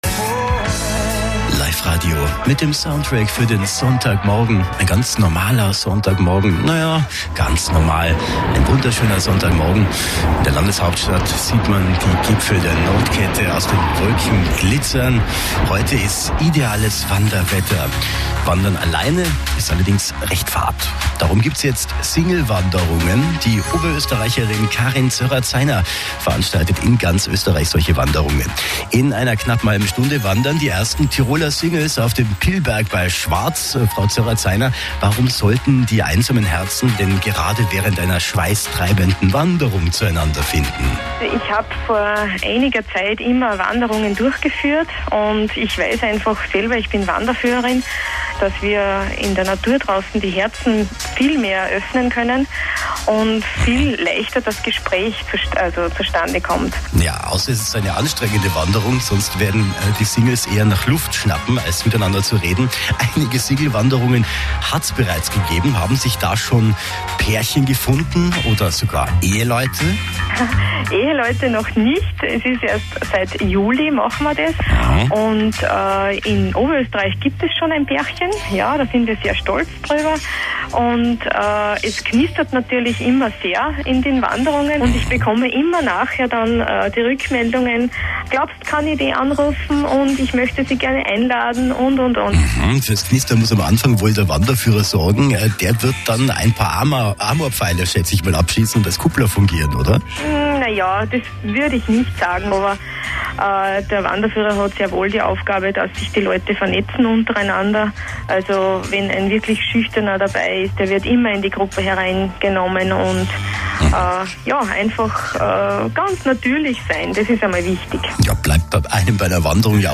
Life Radio Tirol Interview